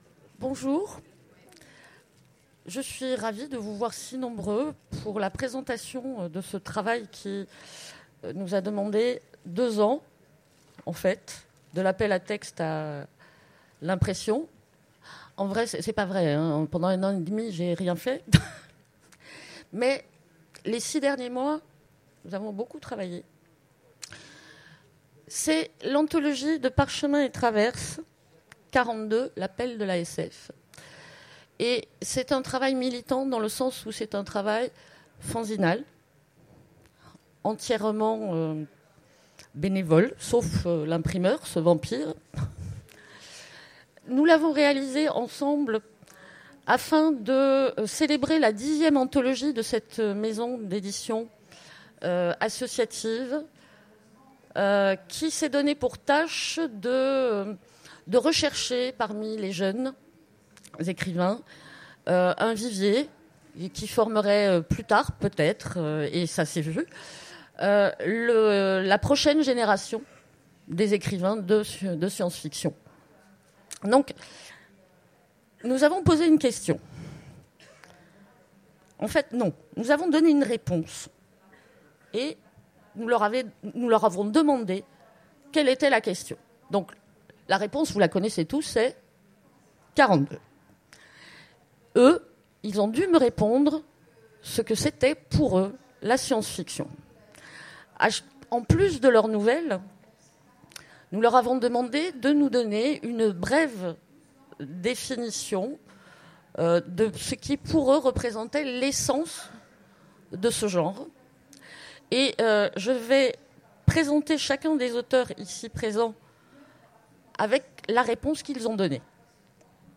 Utopiales 2015 : Conférence Les 42, réalités de la science-fiction française